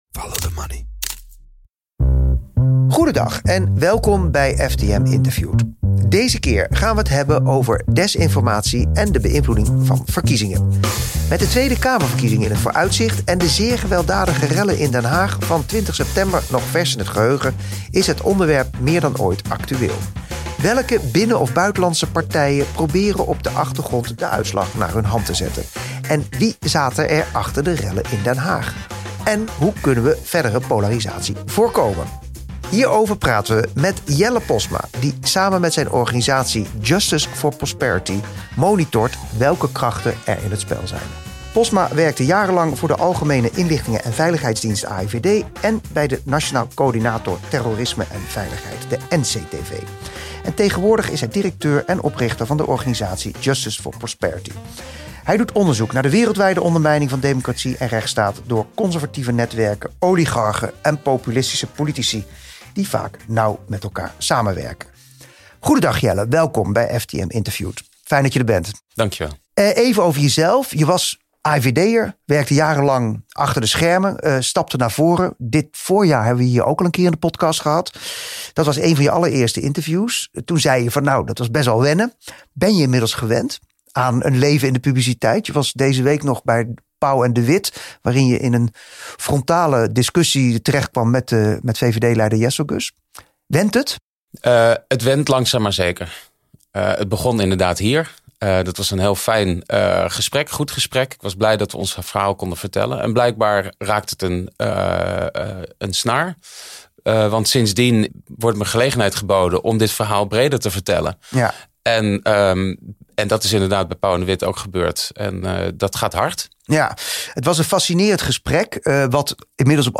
Van ondernemers tot toezichthouders en van wetenschappers tot politici: Follow the Money interviewt.